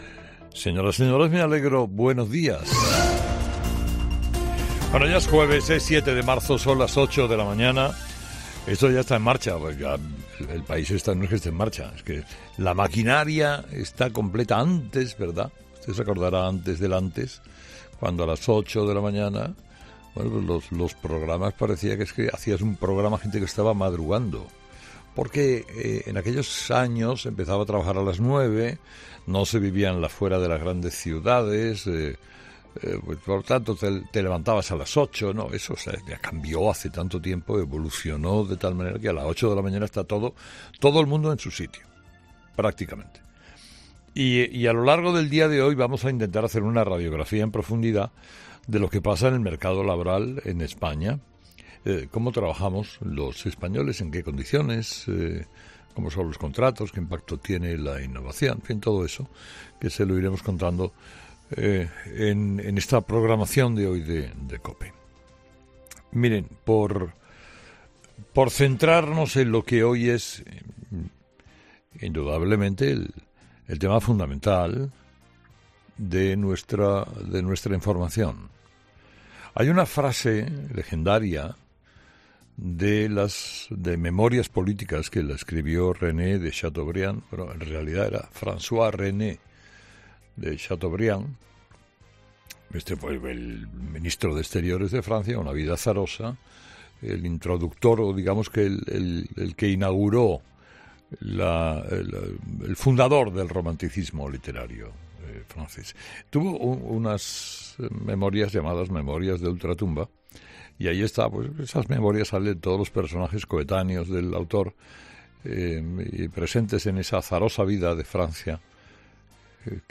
Escucha el monólogo de Carlos Herrera de las 8 del 7 de marzo de 2024